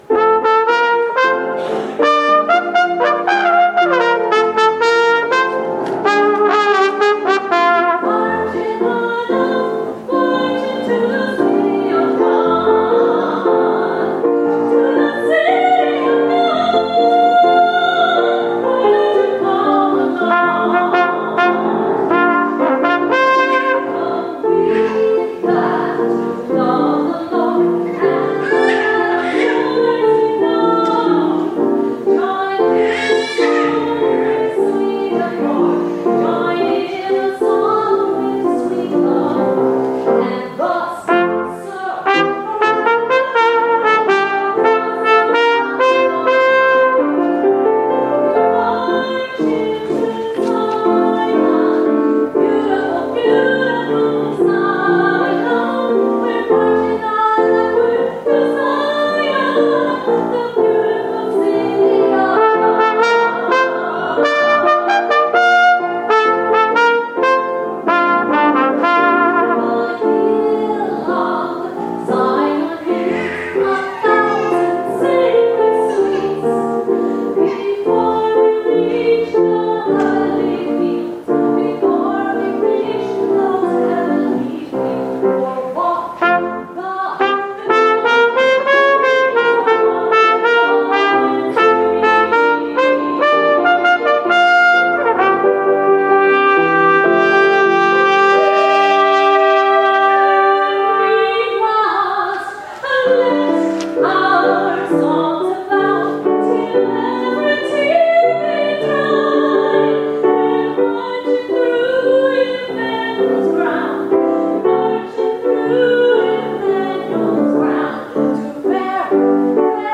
As a bit of a warning, most of the music was performed with very little rehearsal (disclaimer to cover any and all wrong notes, etc.), and all of the recordings were made with the built-in microphone on my iPod (covers balance issues and occasional sags in fidelity).
I added a bit of compression to all of the recordings to help even things out as well. All things considered, most of the songs came out pretty good, but the vocals could all use a little more oomph.
Marchin’ on Up (Spiritual) Arr. Mark Hayes (1953– )
trumpet
piano